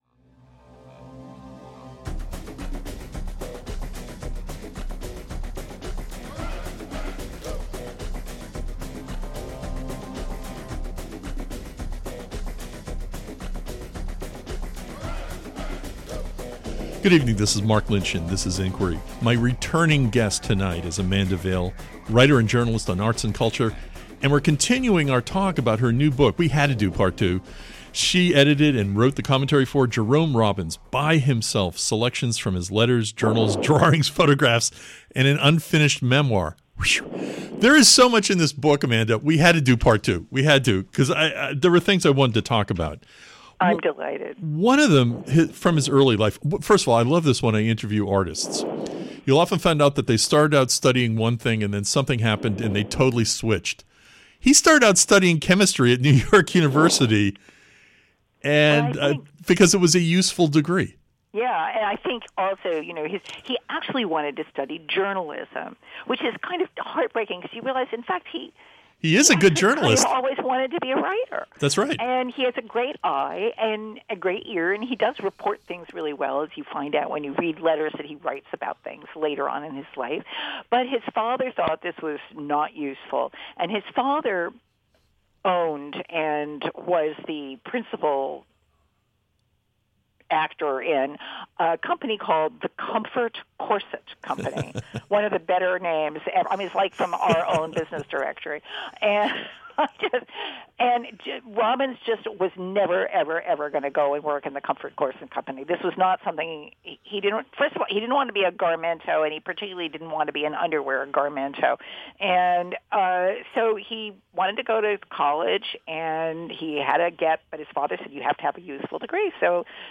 Please join Inquiry for part two of my conversation